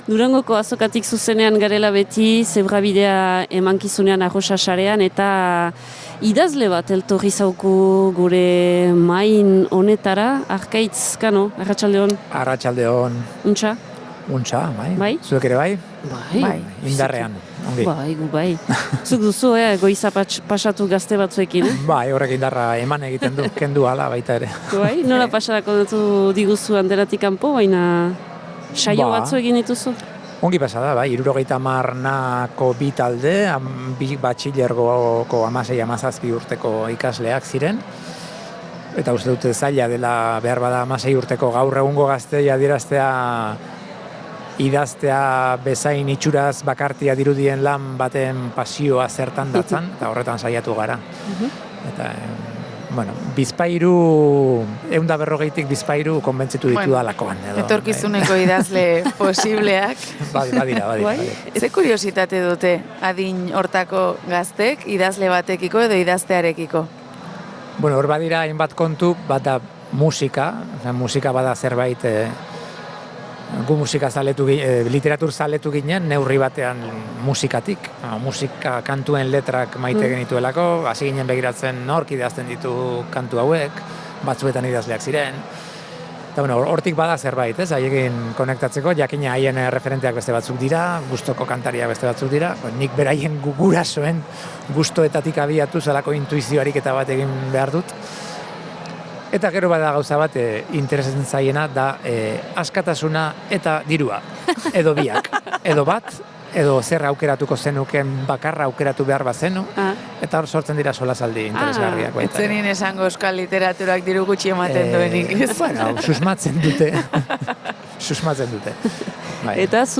Gaurkoan Durangoko Azokatik Zebrabidea saio berezia izan dugu.